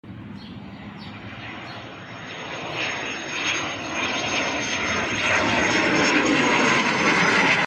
Super Galeb G-4. Serbian Air-Force.